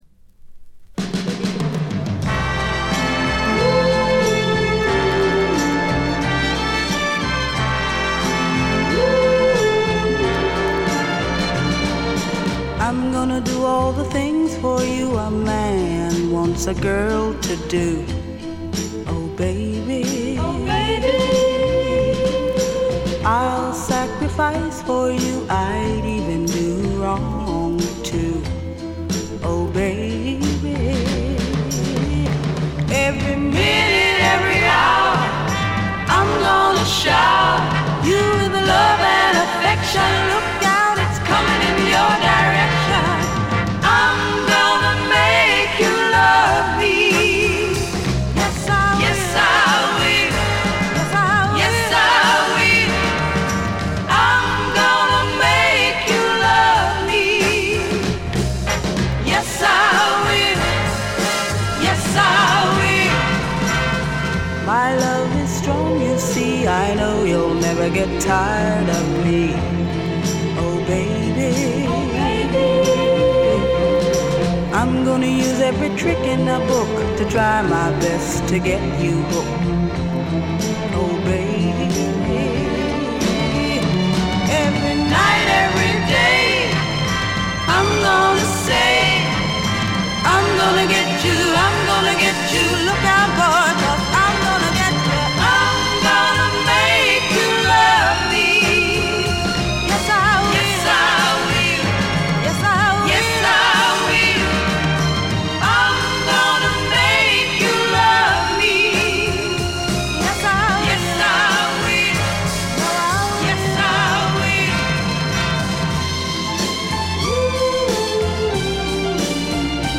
軽微なチリプチ少々。
試聴曲は現品からの取り込み音源です。